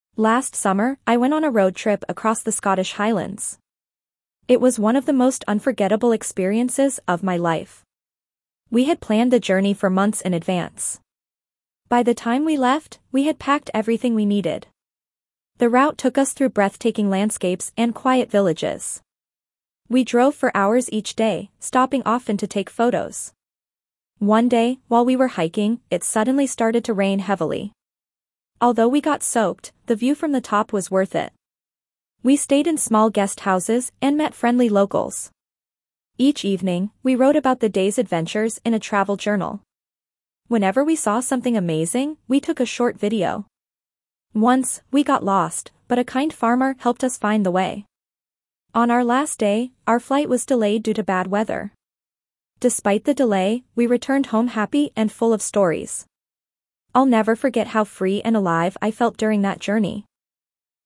Dictation B2 - A Memorable Journey
Your teacher will read the passage aloud.